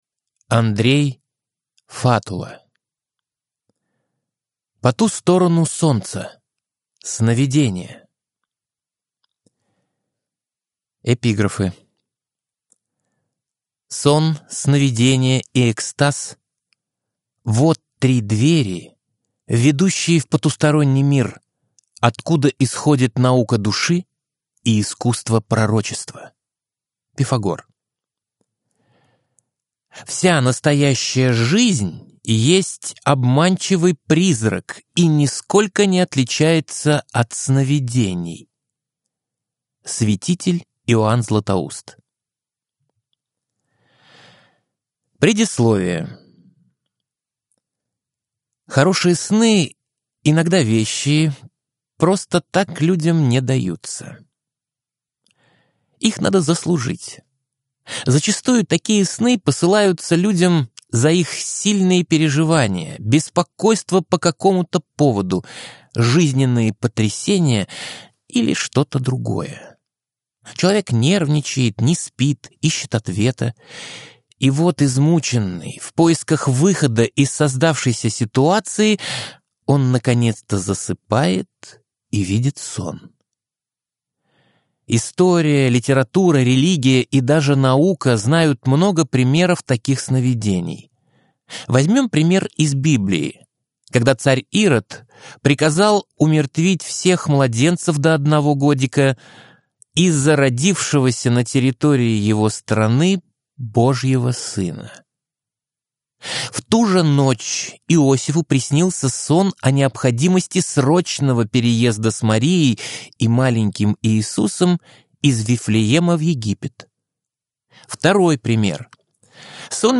Аудиокнига По ту сторону Солнца. Сновидения | Библиотека аудиокниг